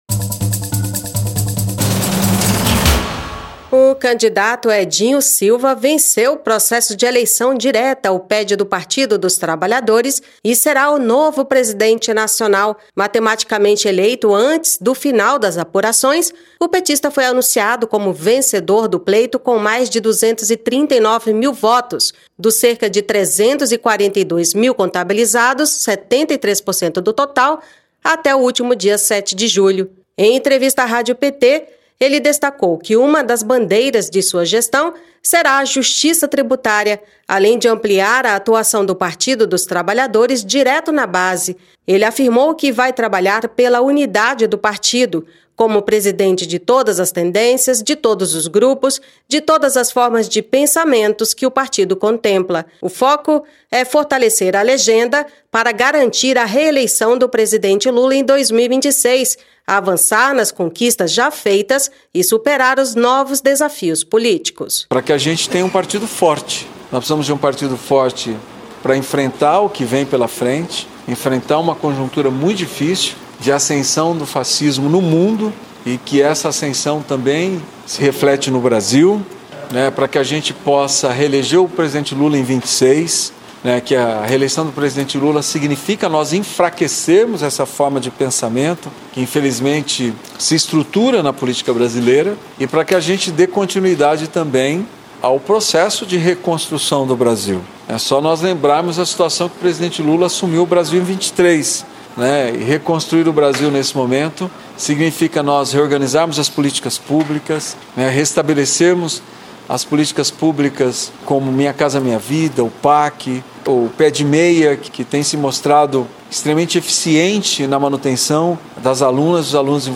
Em entrevista à Rádio PT, ele apontou como prioridades fortalecer o partido para reeleger Lula e enfrentar novos desafios políticos e sociais.